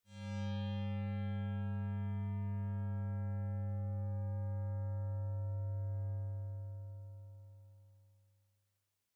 Korg Z1 Slow Bell " Korg Z1 Slow Bell F4 ( Slow Bell 67127)
标签： FSharp4 MIDI音符-67 Korg的-Z1 合成器 单票据 多重采样
声道立体声